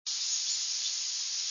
Wood Warbler
Southold, Long Island, NY, Warbler Hill, 5/12/03 (7kb)
warbler_blackburnian_chit_762.wav